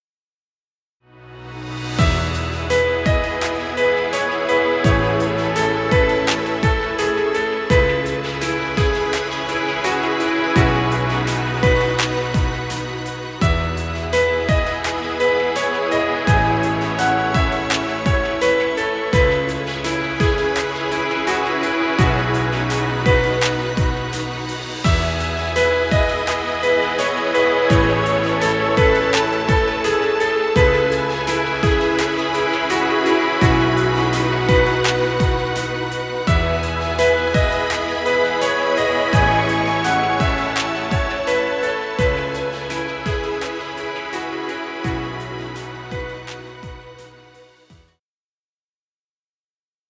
Chillout music. Background music Royalty Free.